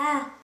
ah-sound-2.mp3